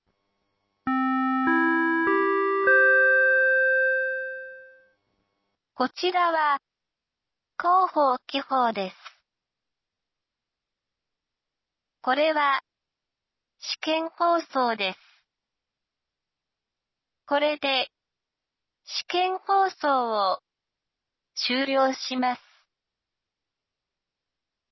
紀宝町防災無線情報